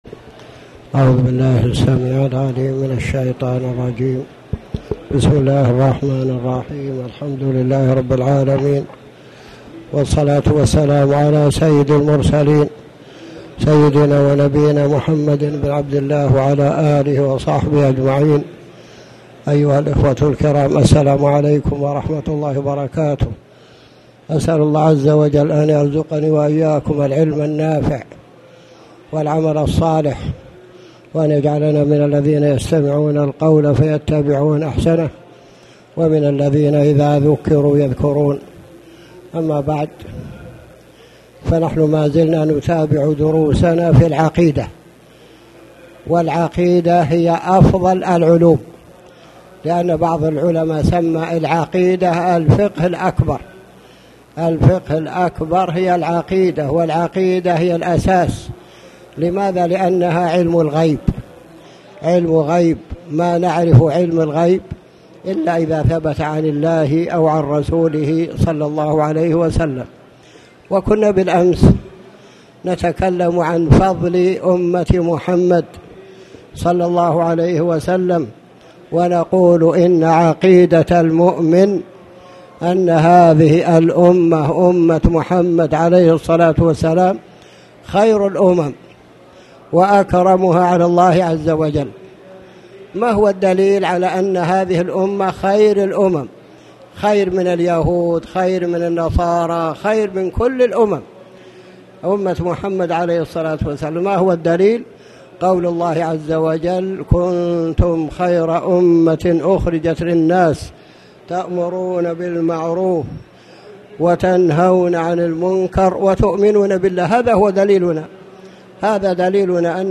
تاريخ النشر ١ ربيع الأول ١٤٣٩ هـ المكان: المسجد الحرام الشيخ